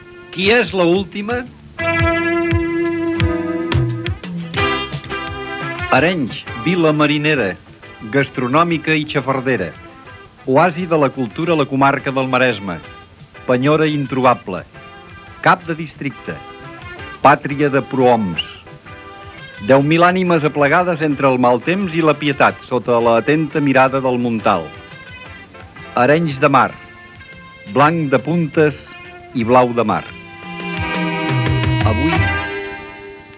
Descripció Careta del serial radiofònic.